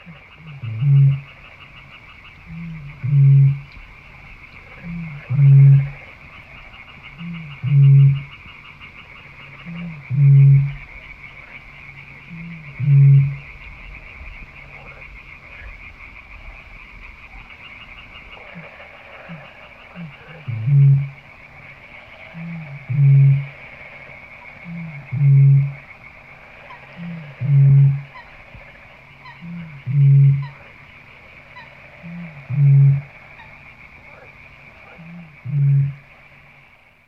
Крики или пение самца большой выпи в ночном болоте среди лягушек многим кажутся пугающими